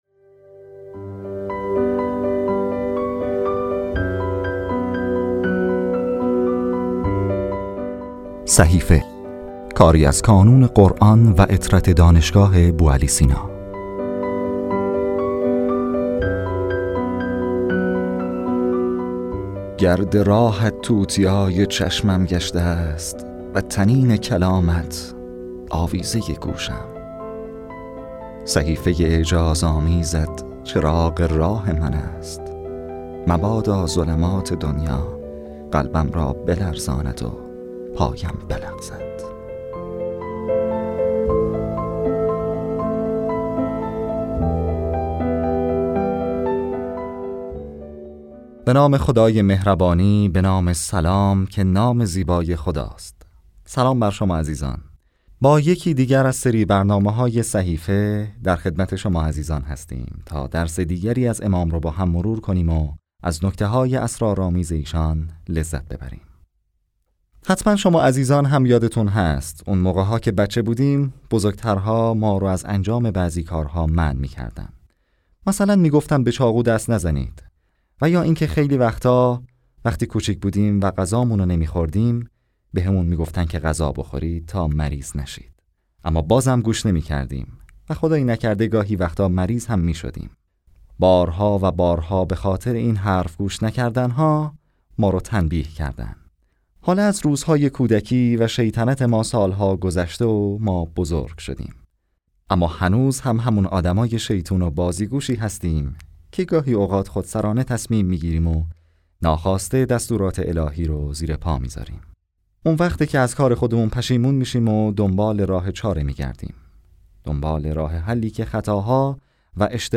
گروه معارف ـ پادکست خوانش ترجمه صحیفه سجادیه با محوریت دعای دوازدهم منتشر شد.